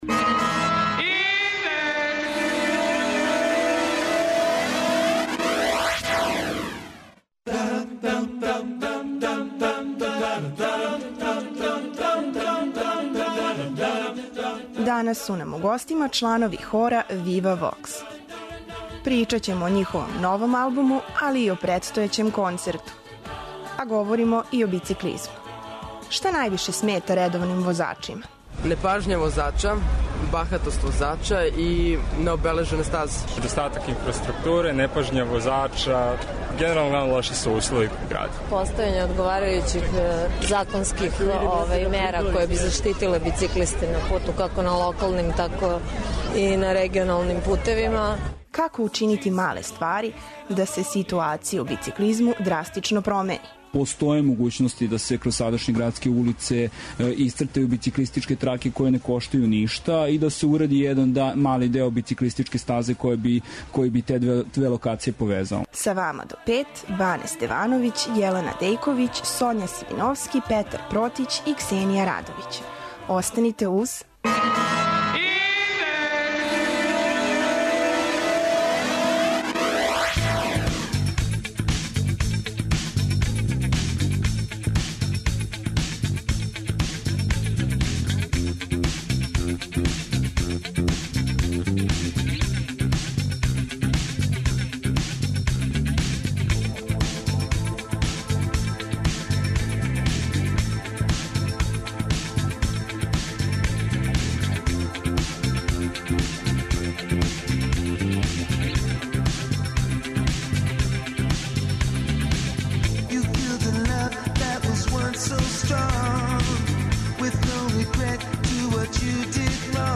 Гости данашњег Индекса су чланови хора Вива Вокс.